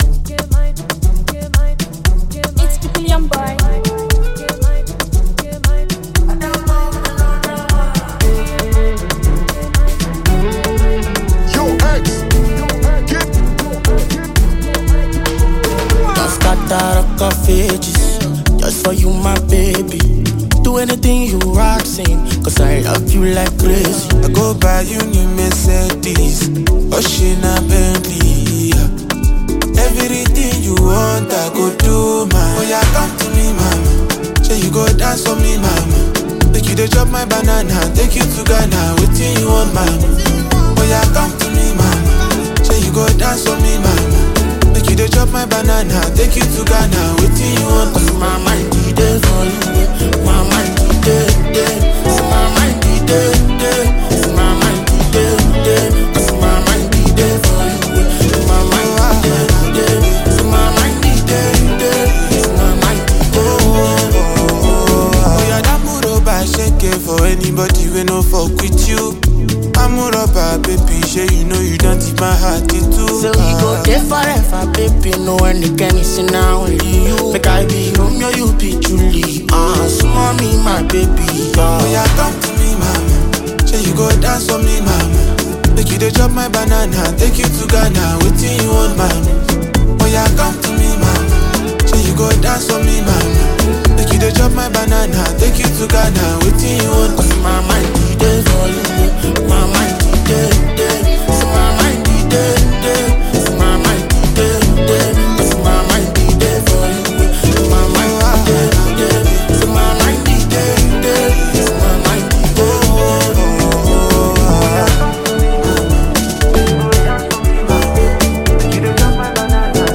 Afrobeats
a smooth, mid-tempo Afro-fusion jam
laid-back beat